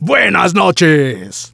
el_primo_kill_01.wav